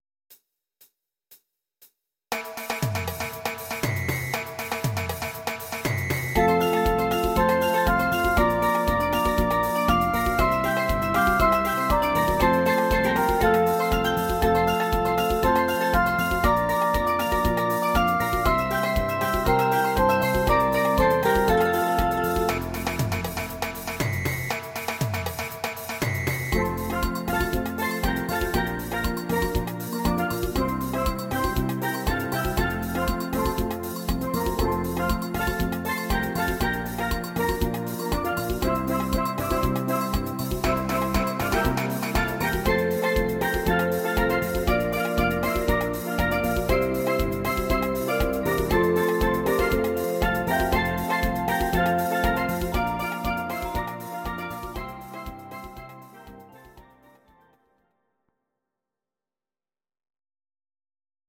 These are MP3 versions of our MIDI file catalogue.
Please note: no vocals and no karaoke included.
instr. Steel Drums